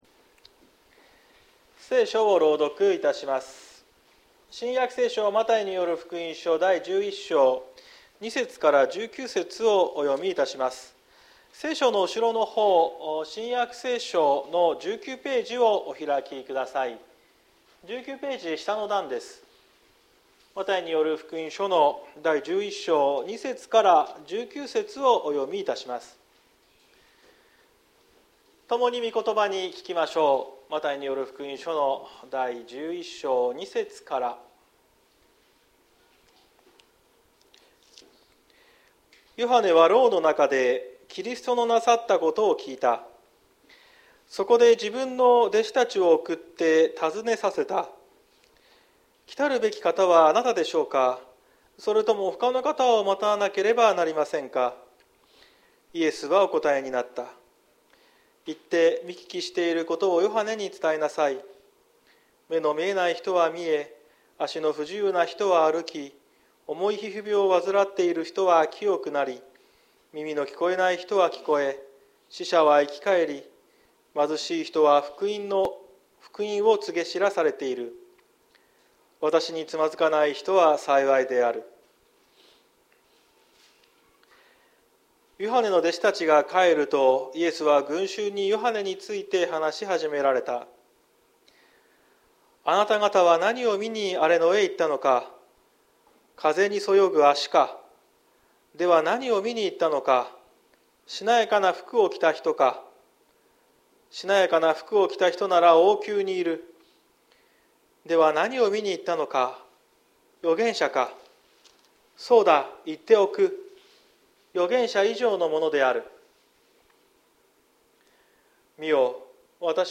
2023年12月31日朝の礼拝「来たるべきお方」綱島教会
綱島教会。説教アーカイブ。